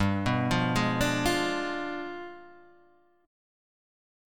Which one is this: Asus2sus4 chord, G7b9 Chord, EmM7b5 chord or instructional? G7b9 Chord